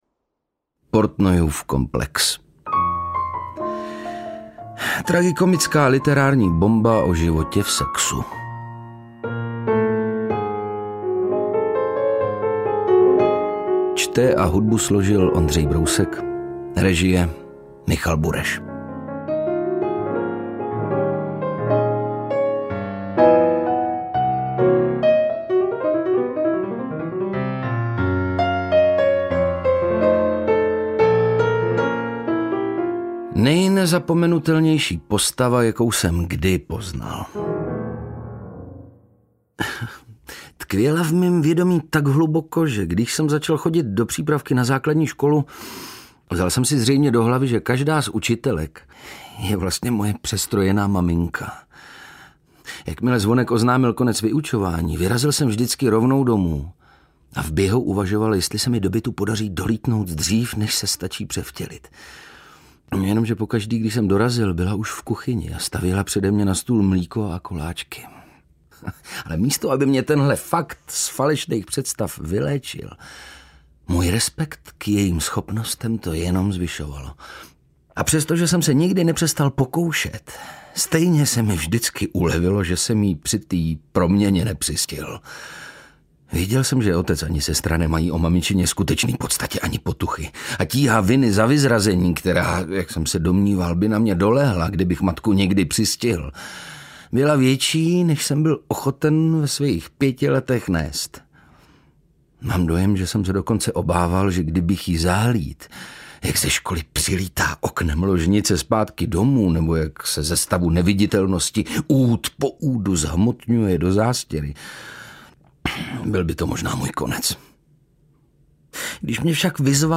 Audiobook
Read: Ondřej Brousek